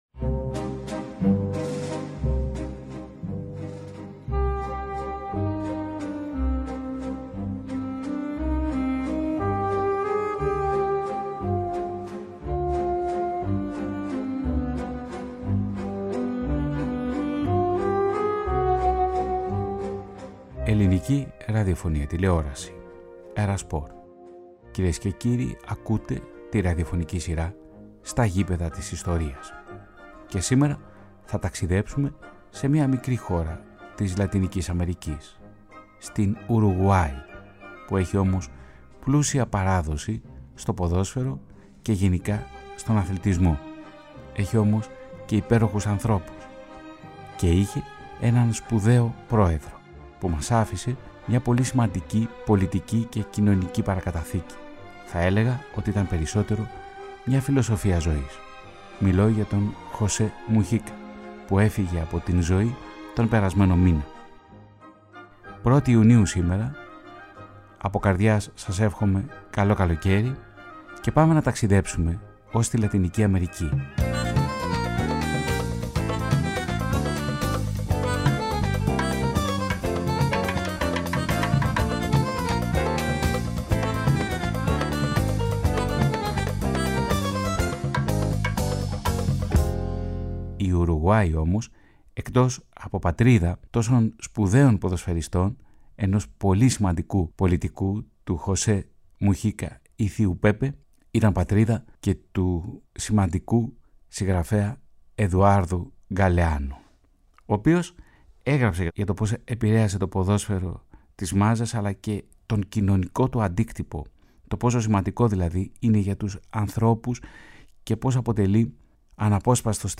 Στο πρώτο μέρος, το ραδιοφωνικό ντοκιμαντέρ φωτίζει δύο φιγούρες του Μεσοπολέμου, δύο από τους σημαντικότερους παίκτες της παγκόσμιας Ιστορίας του ποδοσφαίρου, τον Πέδρο Πετρόνε Σκιαβόνε και τον Έκτορ Πέδρο Σκαρόνε Μπερέτα.